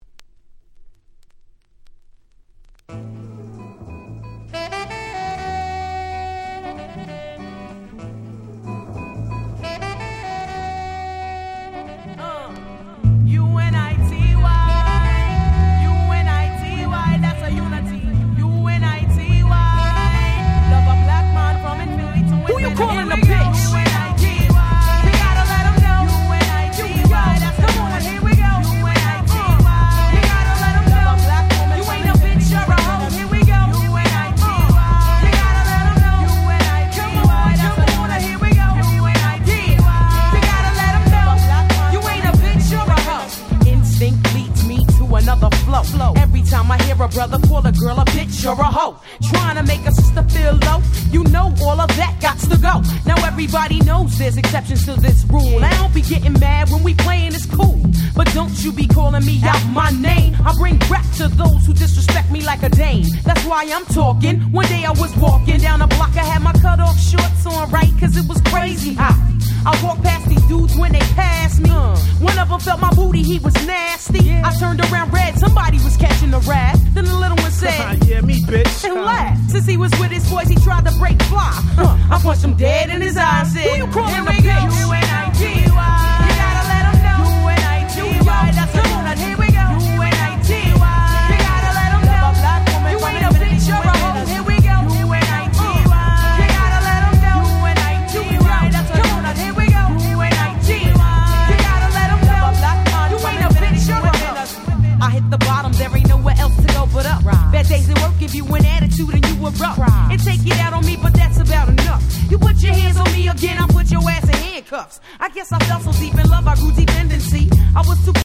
93' Smash Hit Hip Hop !!
Boom Bap